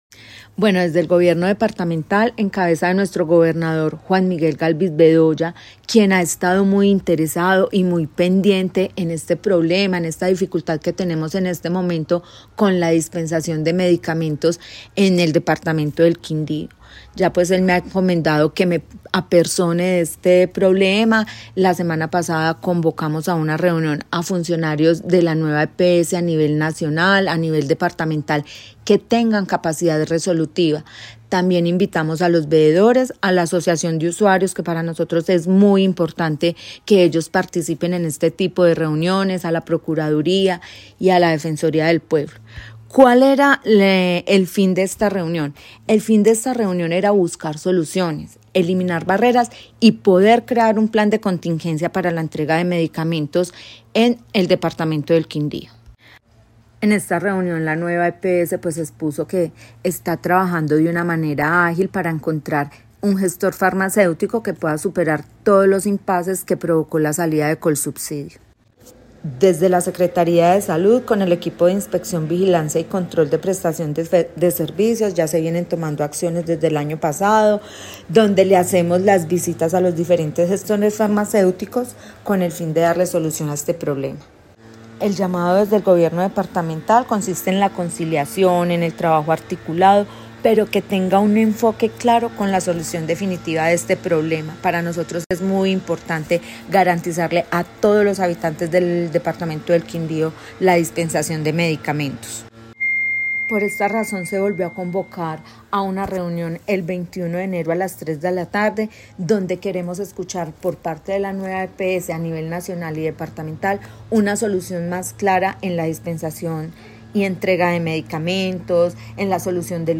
Luisa Arcila, secretaria de salud del Quindío
En 6AM/W de Caracol Radio Armenia hablamos con Luisa Arcila, secretaria de salud del Quindío que manifestó “esta dificultad que tenemos en este momento con la dispensación de medicamentos en el departamento del Quindío.